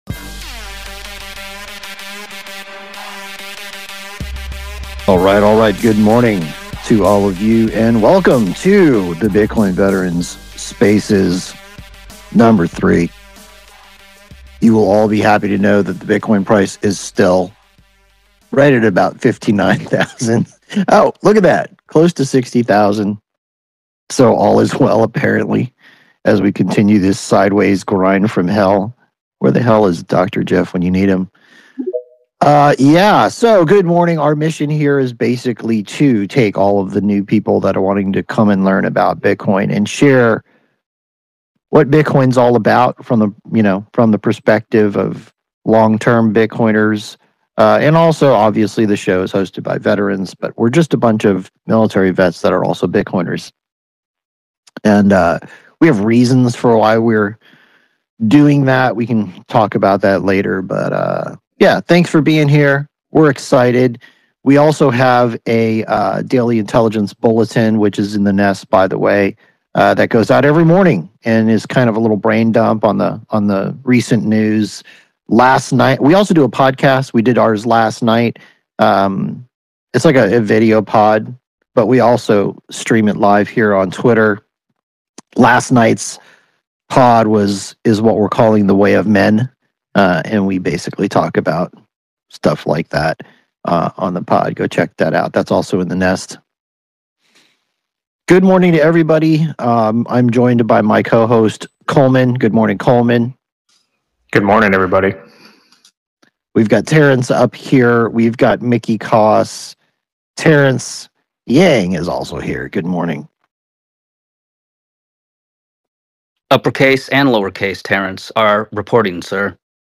In this episode of the podcast, we discussed various topics related to Bitcoin, including the current price, the concept of unrealized capital gains tax, and the implications of Bitcoin for long-term investors. The hosts, who are military veterans and Bitcoin enthusiasts, shared their insights on the importance of educating others about Bitcoin and its potential impact on financial freedom. The conversation also touched on the challenges of engaging with skeptics and the need for ongoing education and support within organizations that are considering investing in Bitcoin.